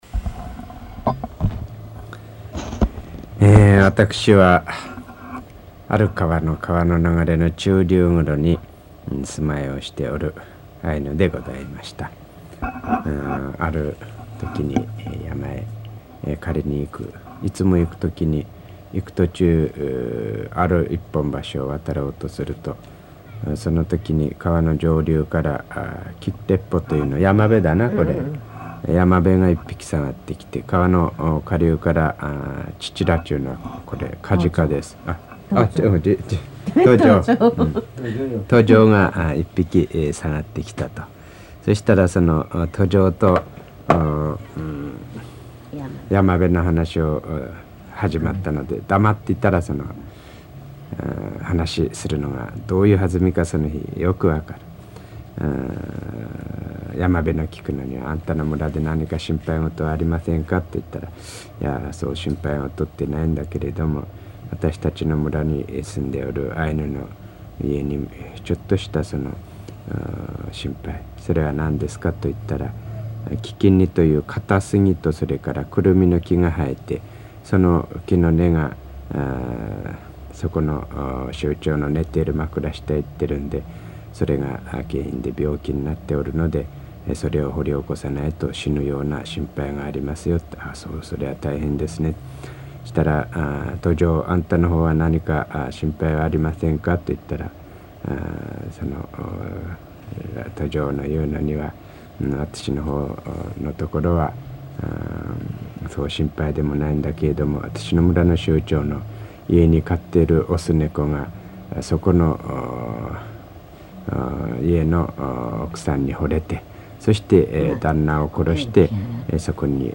[5-9 解説 commentary] 日本語音声 5:05